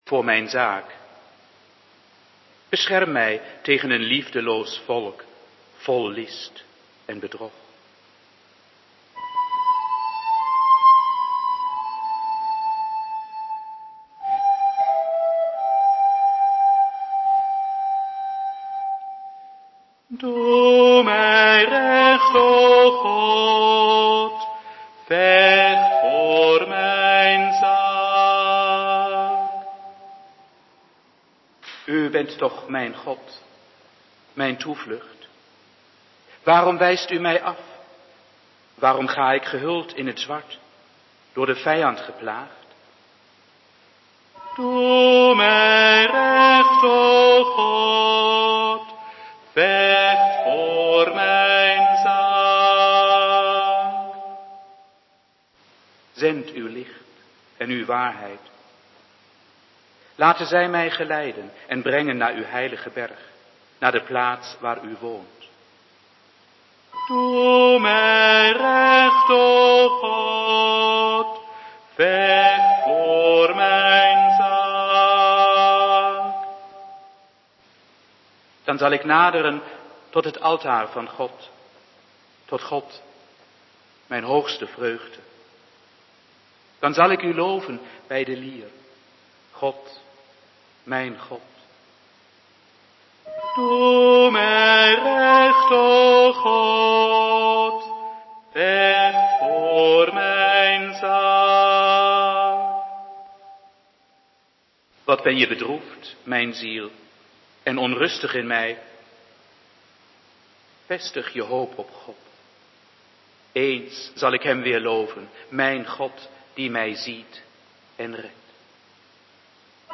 Online Kerkdienst Caeciliakapel
Beste mensen, Aanstaande zondag is het zondag judica. Er staat een online dienst klaar voor u. Deze is opgenomen in de Caeciliakapel.